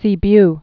(sē-by)